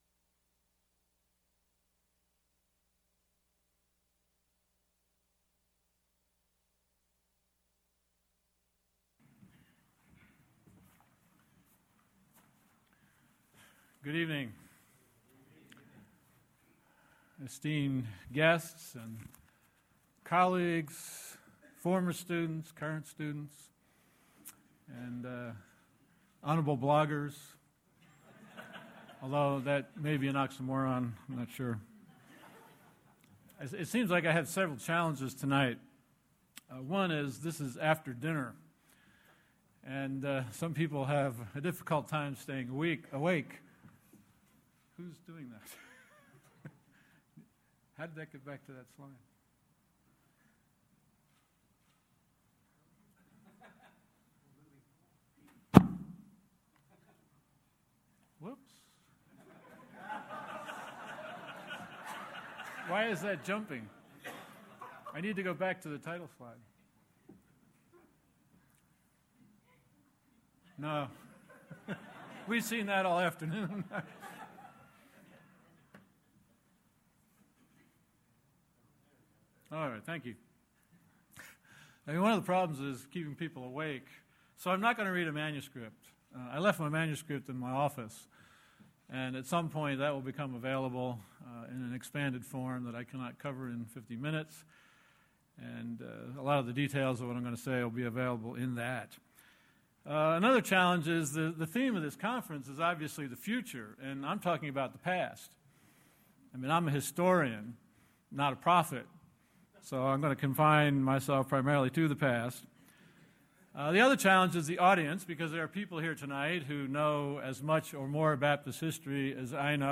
Union University Address: Reflections on 400 Years of the Baptist Movement: Who We Are. What We Believe.